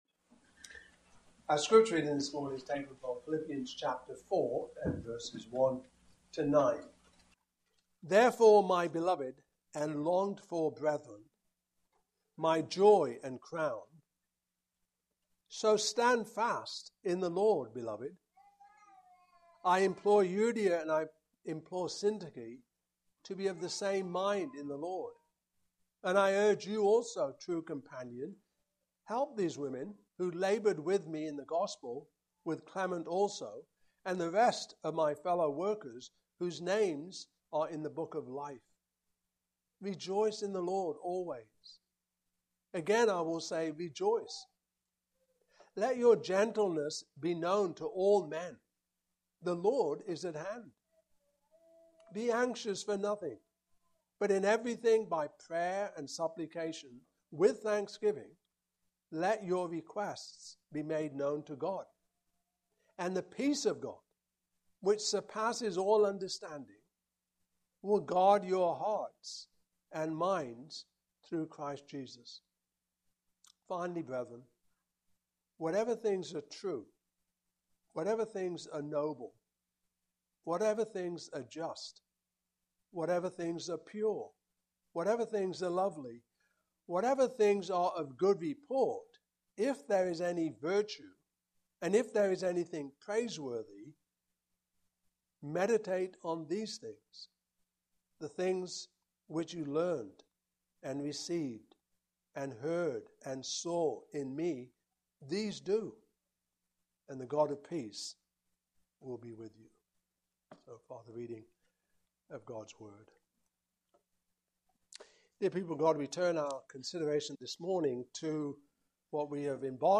Fruit of the Spirit Passage: Philippians 4:1-9 Service Type: Morning Service Topics